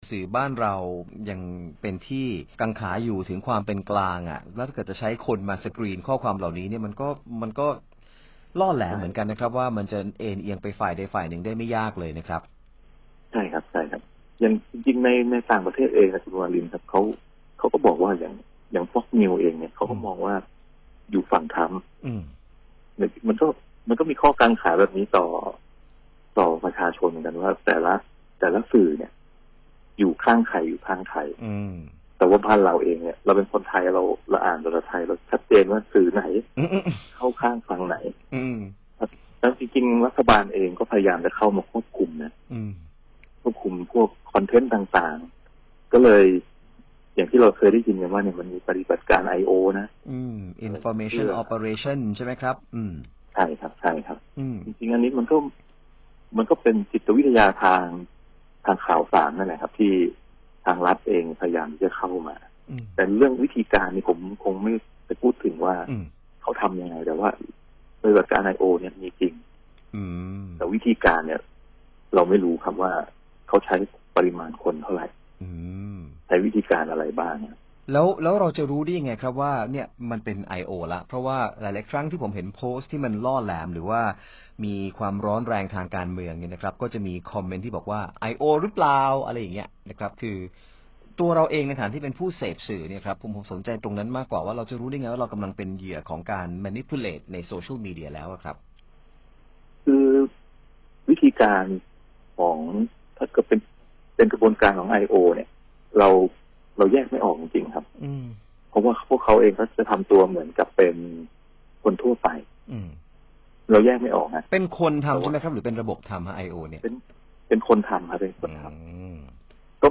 สัมภาษณ์